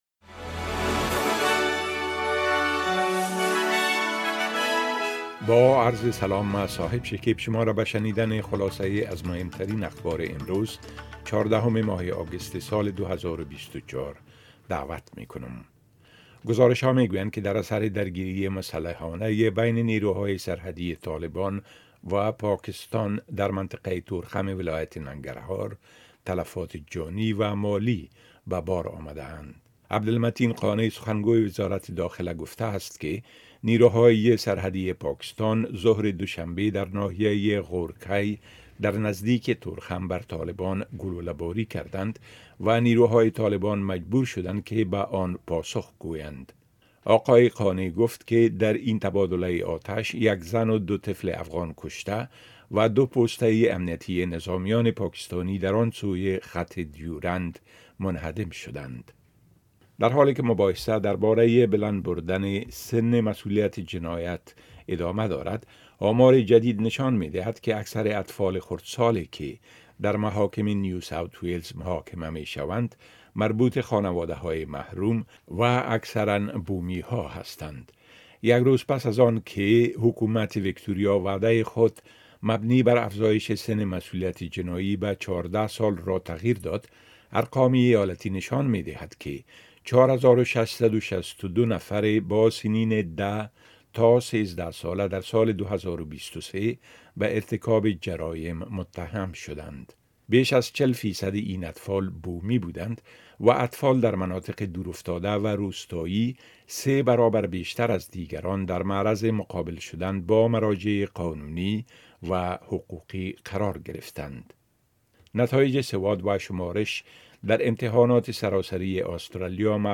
خلاصۀ مهمترين اخبار روز از بخش درى راديوى اس بى اس
10 am News Update Source: SBS / SBS Filipino